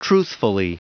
Prononciation du mot truthfully en anglais (fichier audio)
Prononciation du mot : truthfully